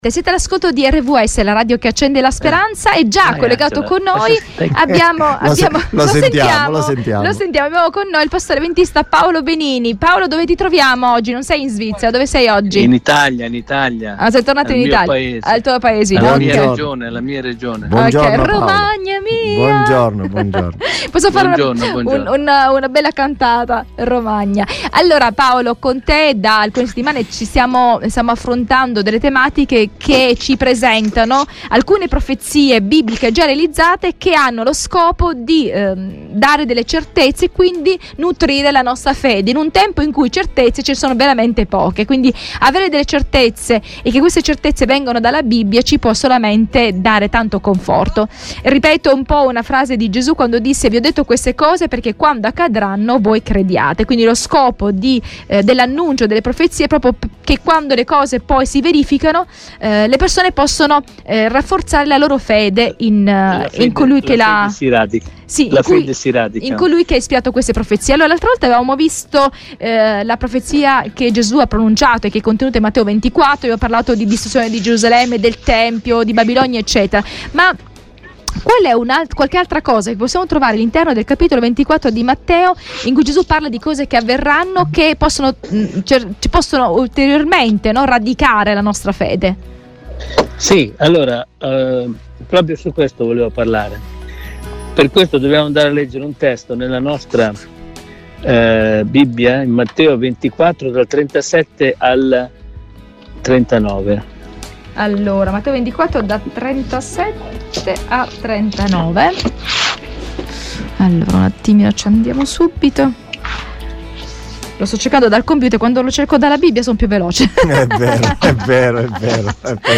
Le profezie realizzate sono le basi su cui fondare le nostre certezze. Segui il dialogo con il pastore avventista